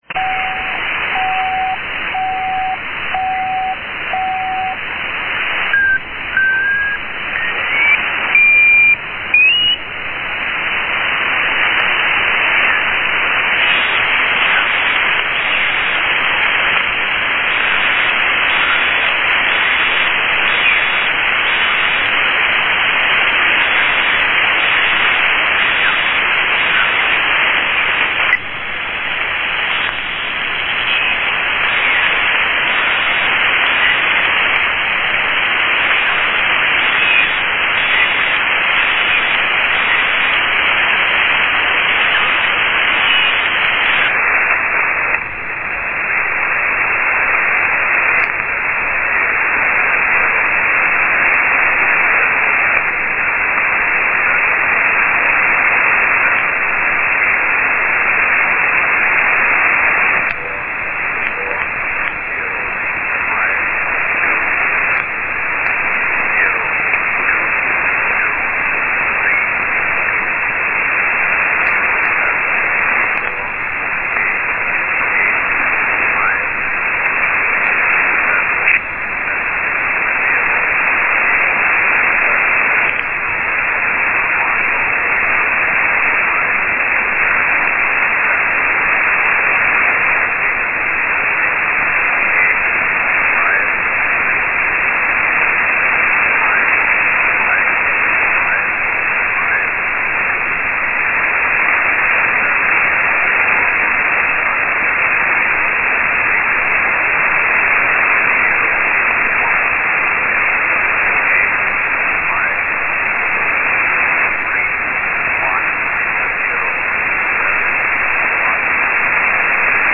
3. Morse with E06/E07 voice